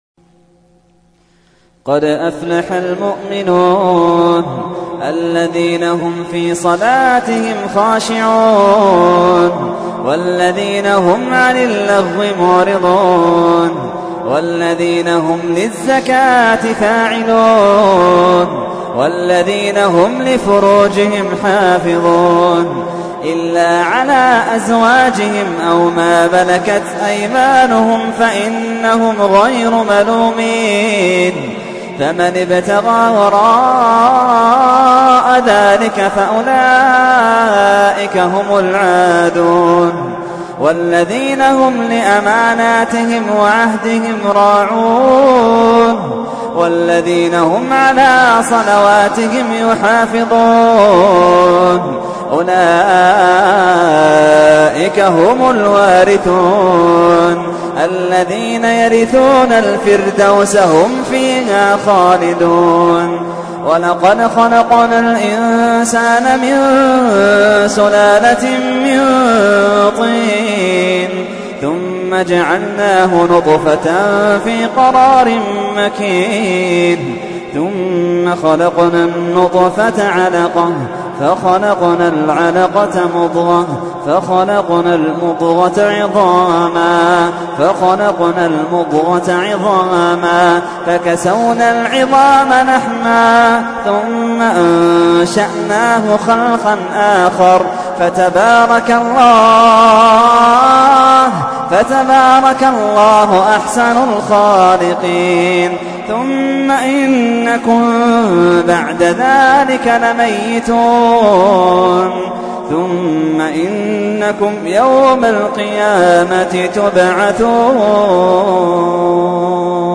تحميل : 23. سورة المؤمنون / القارئ محمد اللحيدان / القرآن الكريم / موقع يا حسين